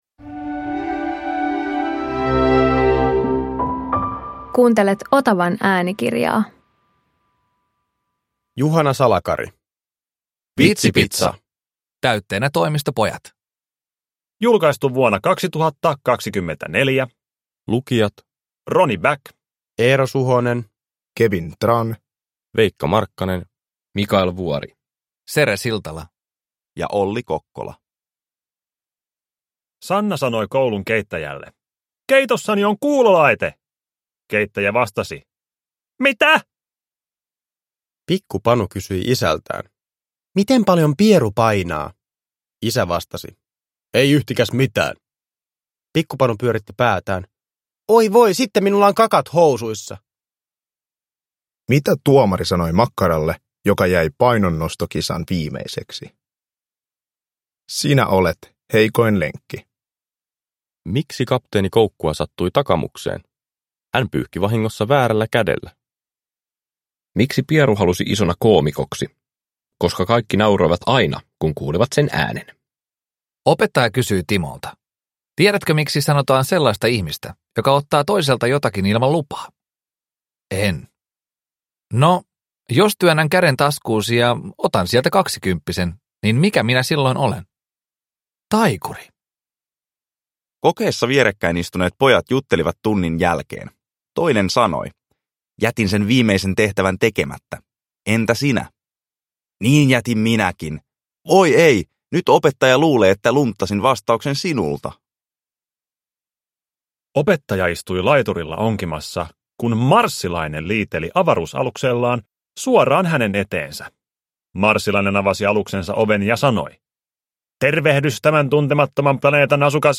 Vitsipitsa - täytteenä Toimistopojat – Ljudbok